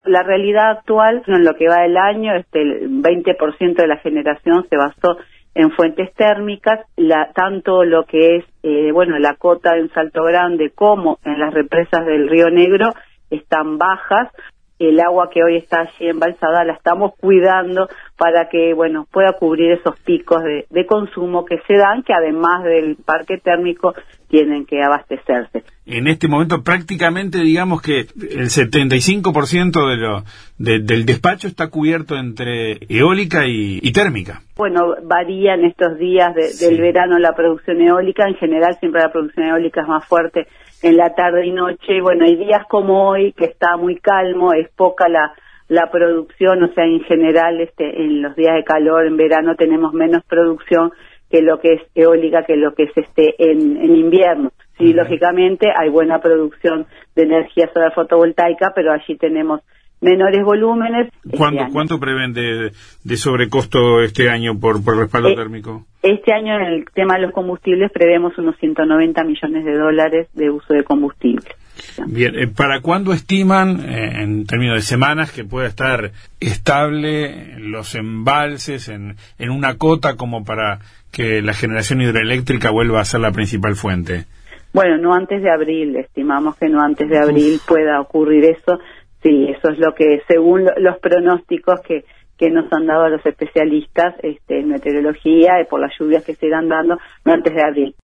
La presidenta del directorio de UTE, Silvia Emaldi, explicó en Informativo Uruguay que la alta demanda estacional de energía se combina con “una de las 10 peores sequías de los últimos cien años” para la generación hidroeléctrica.